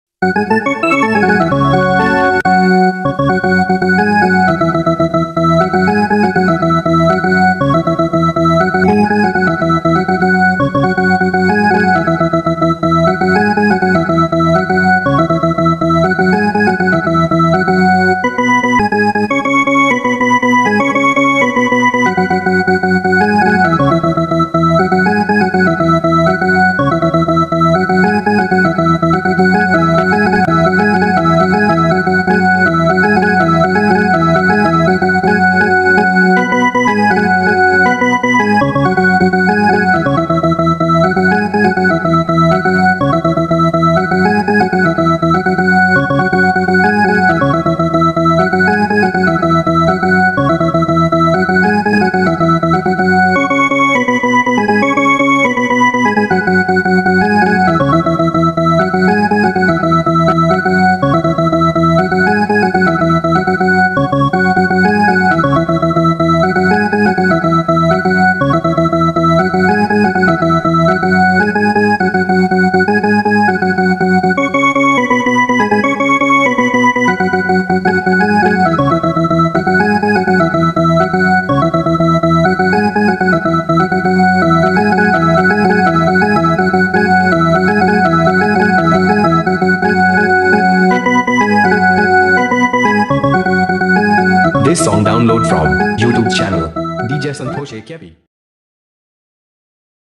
CategoryTelangana Folk Songs